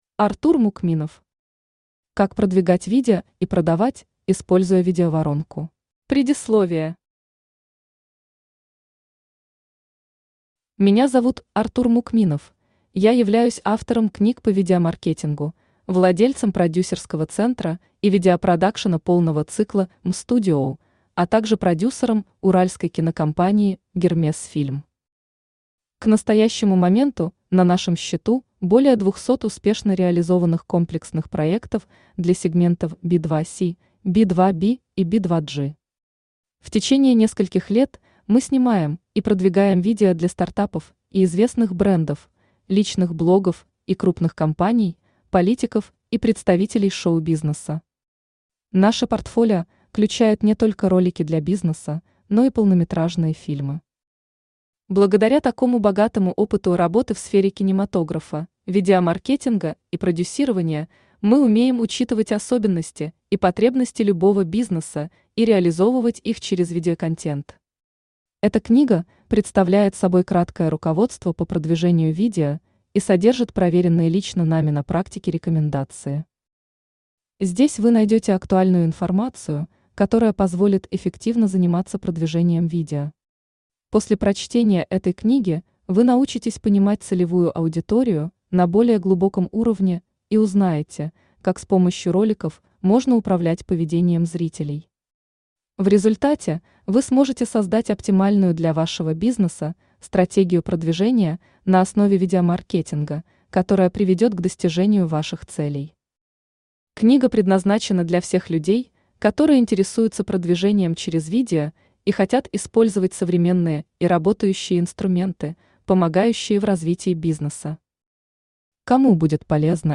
Аудиокнига Как продвигать видео и продавать, используя видеоворонку | Библиотека аудиокниг
Aудиокнига Как продвигать видео и продавать, используя видеоворонку Автор Артур Мукминов Читает аудиокнигу Авточтец ЛитРес.